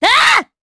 Xerah-Vox_Attack1_Madness_jp.wav